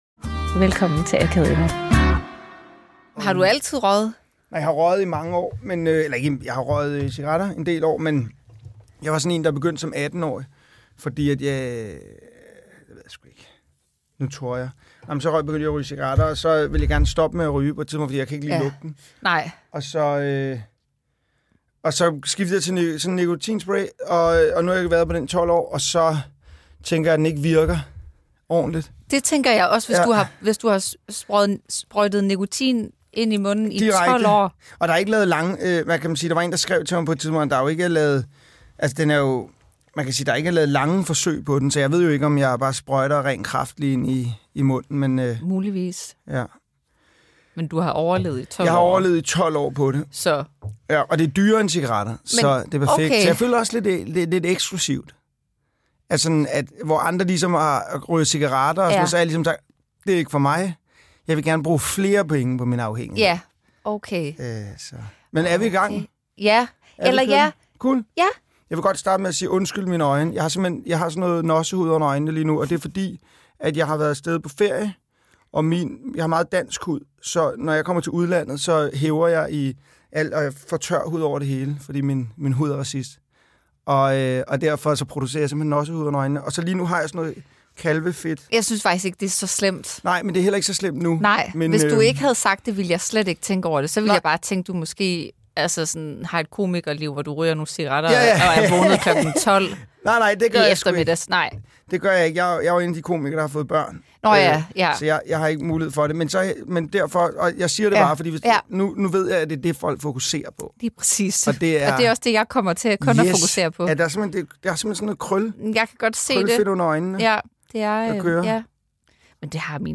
Komiker Christian Fuhlendorff snakker uafbrudt og stiller derfor spørgsmålstegn ved den akavede stemning til sidst.